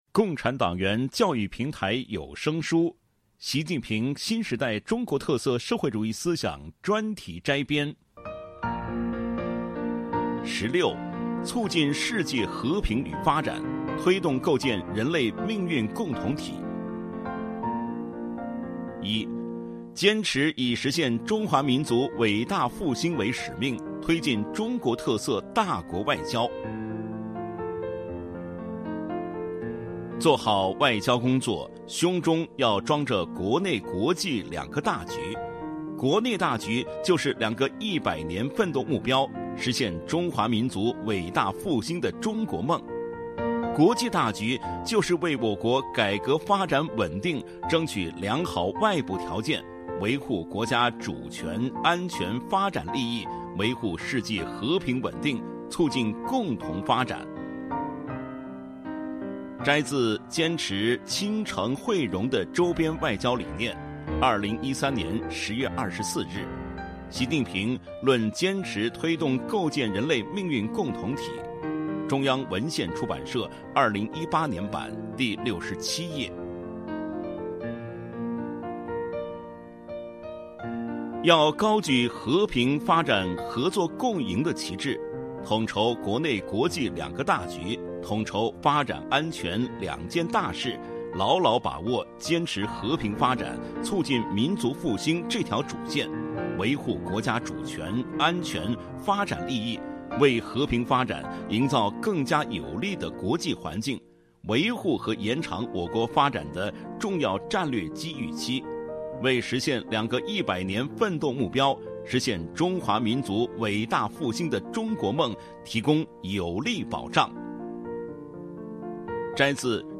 主题教育有声书 《习近平新时代中国特色社会主义思想专题摘编》（74）.mp3